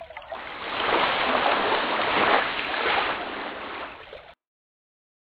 Lakeshore_mono_01.ogg